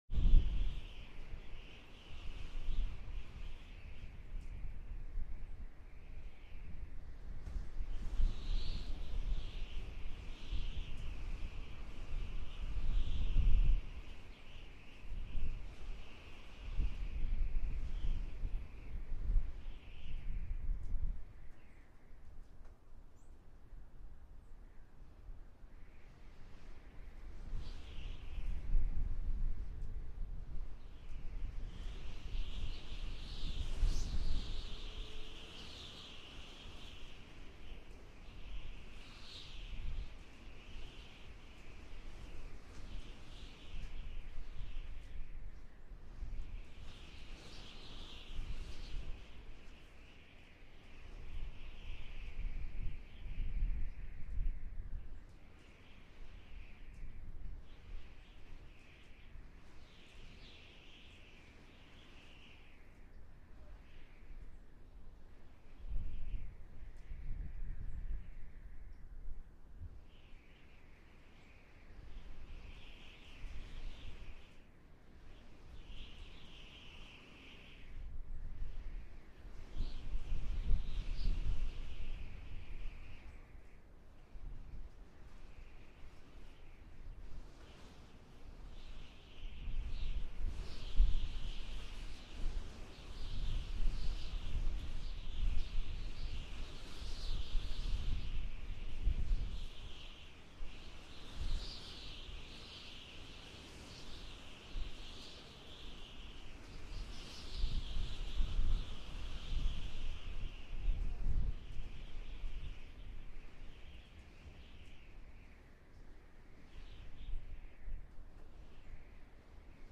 دانلود صدای باد 2 از ساعد نیوز با لینک مستقیم و کیفیت بالا
جلوه های صوتی